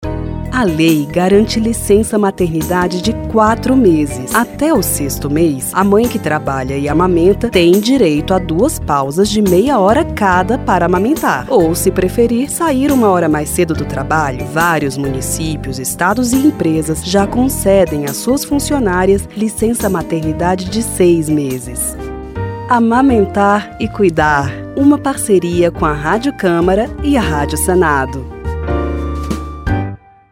Por isso, a Rádio Câmara, em parceria com a Rádio Senado, lança a campanha “Amamentar e cuidar”. São cinco spots de 30 segundos cada.